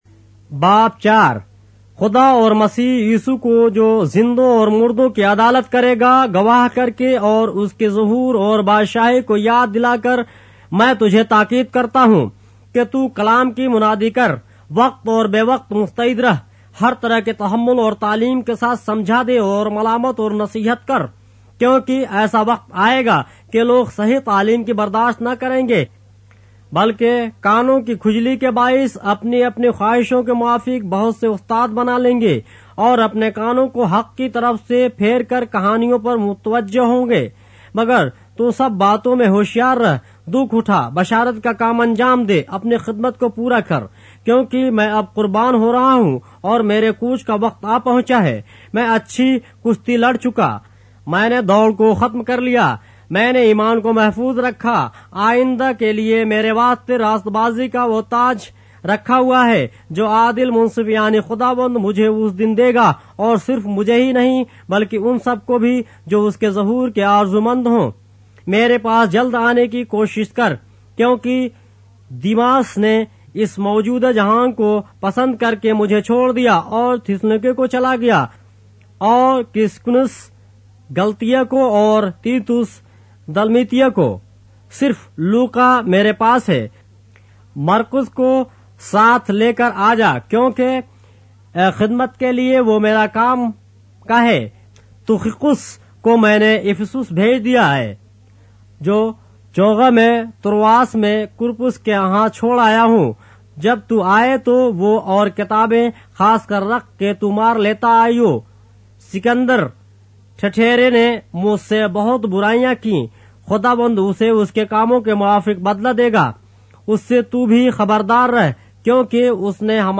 اردو بائبل کے باب - آڈیو روایت کے ساتھ - 2 Timothy, chapter 4 of the Holy Bible in Urdu